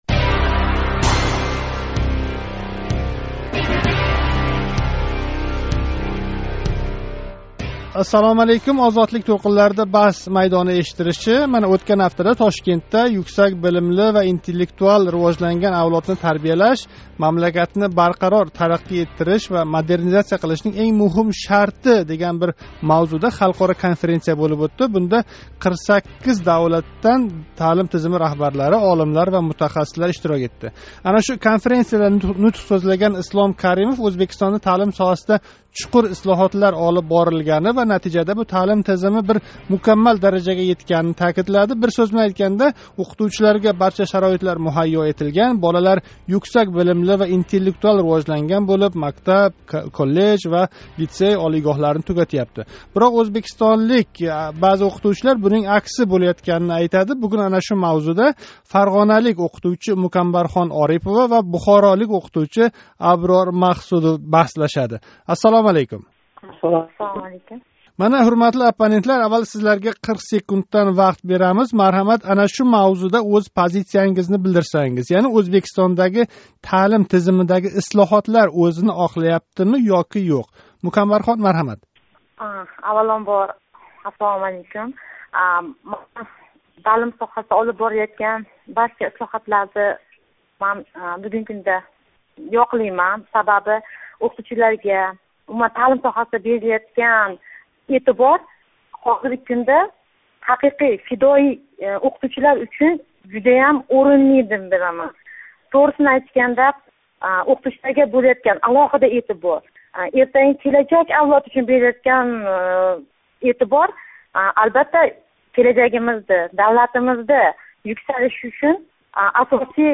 Баҳс майдонининг навбатдаги сонида бухоролик ва фарғоналик мактаб ўқитувчилари мамлакат таълим тизимида олиб борилаётган ислоҳотлар қанчалик ўзини оқлагани ҳақида баҳслашади.